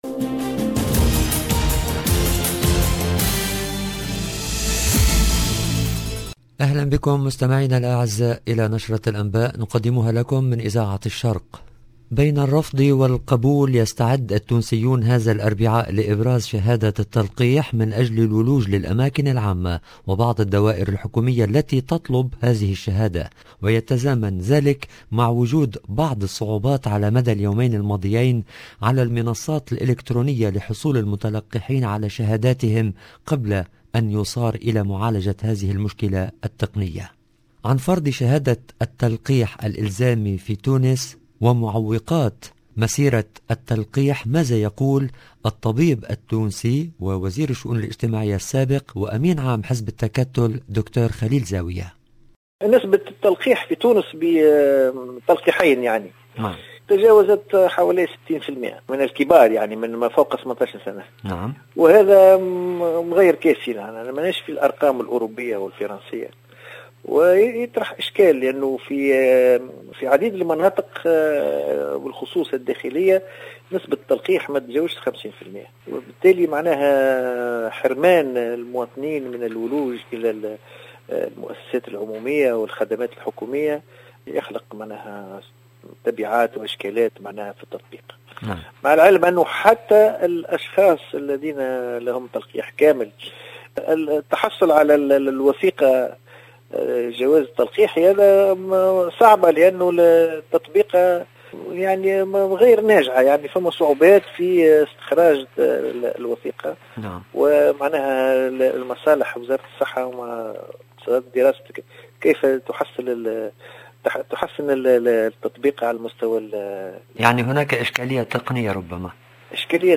LE JOURNAL EN LANGUE ARABE DU SOIR DU 21/12/21